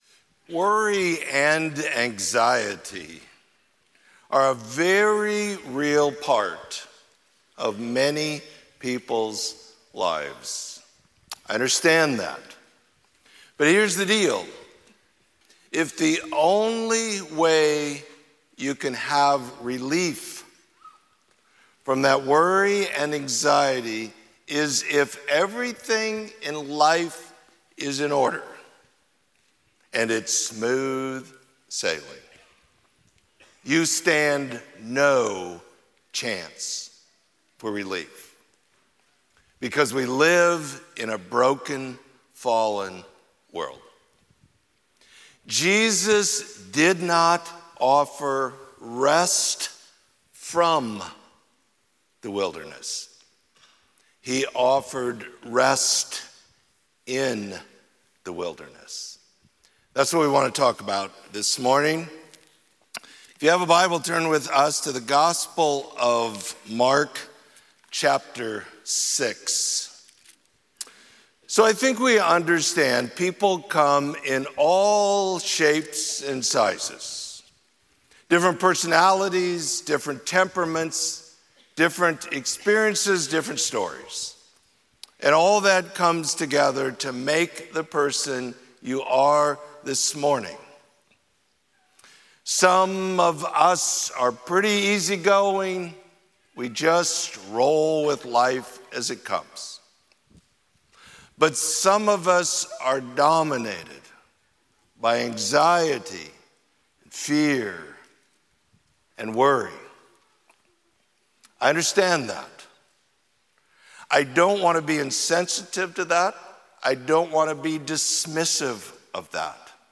Sermon: Worry and Anxiety